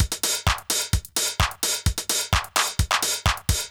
CLF Beat - Mix 3.wav